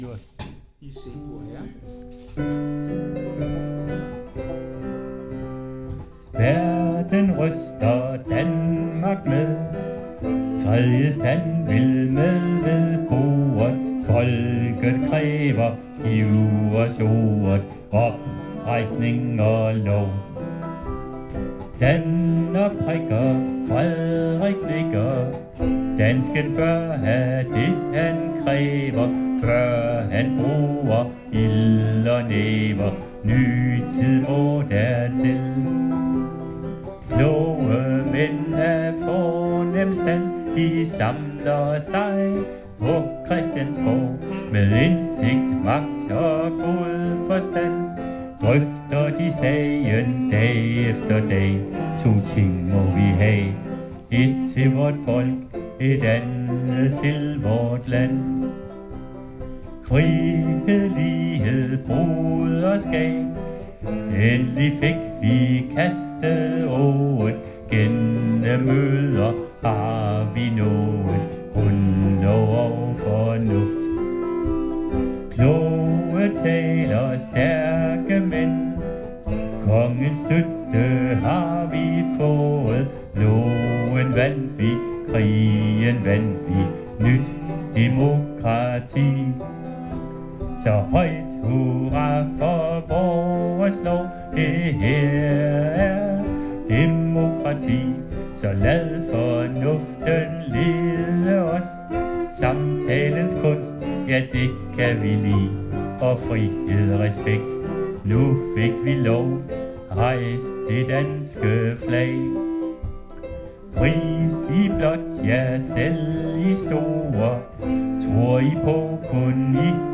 Øveaften 16. oktober 2024: